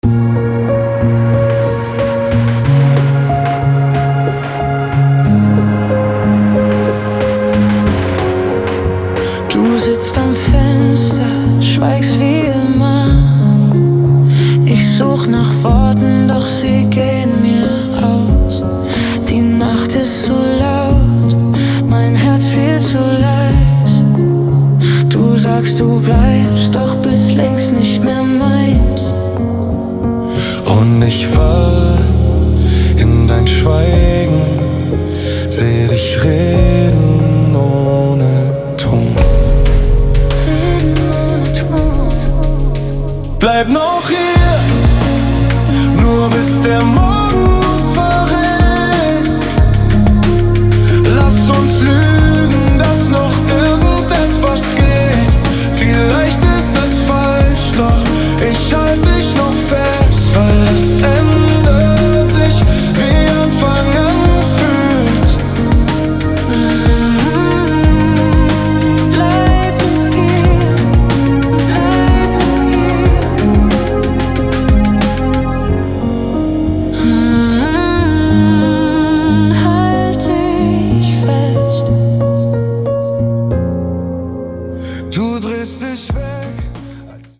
Und wir lieben es, zu zweit zu singen.